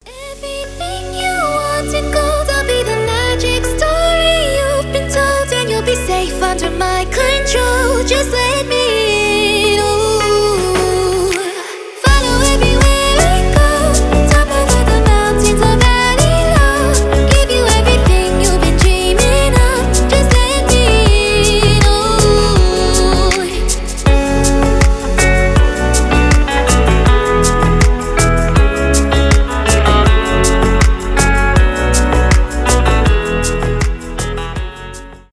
поп , сказочные , красивый женский голос